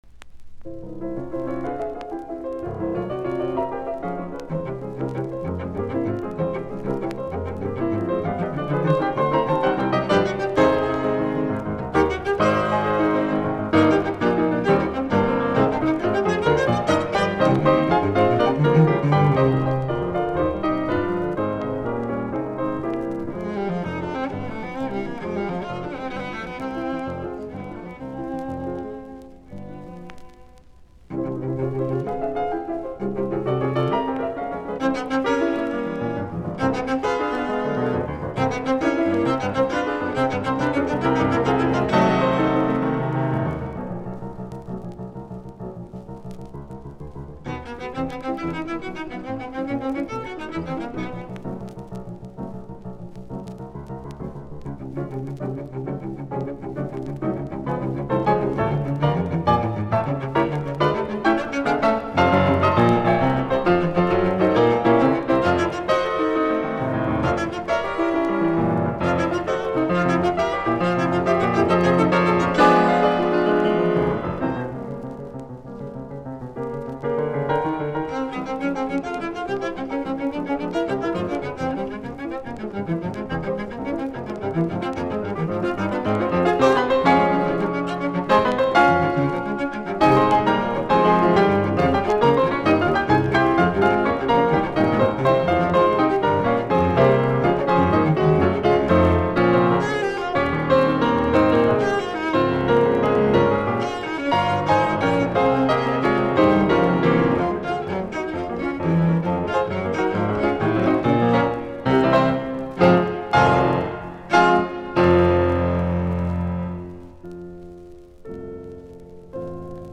Sonaatit, sello, piano, nro 2, op99, F-duuri
musiikkiäänite
Soitinnus: Sello, piano.